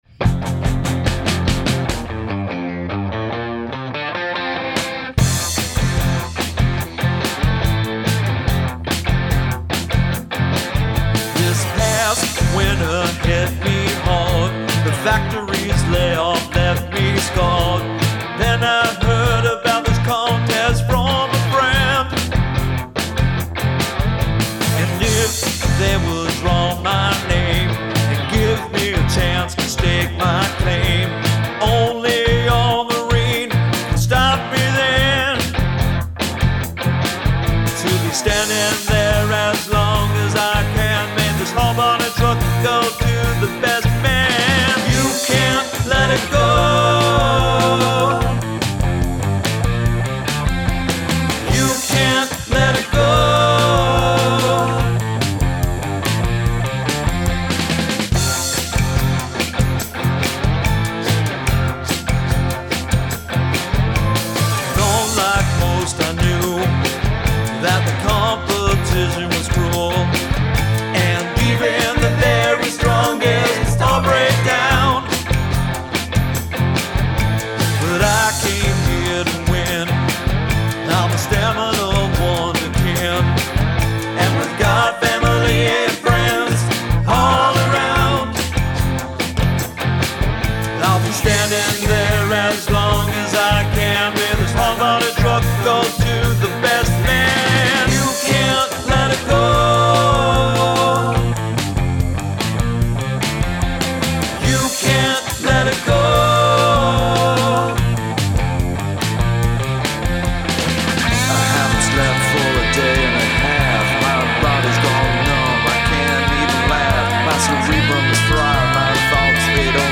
This song feels kind of rigid.
another good opening salvo, great riff.